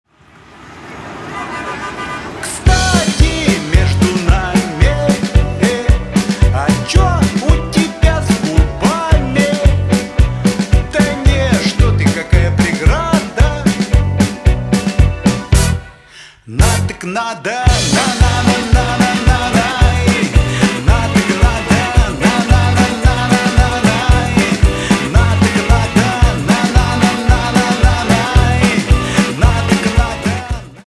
рок
русский рок , гитара , барабаны